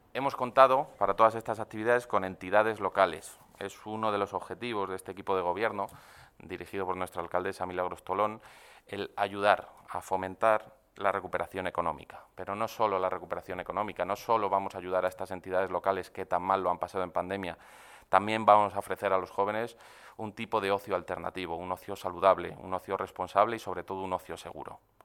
AUDIOS. Pablo García, concejal de Juventud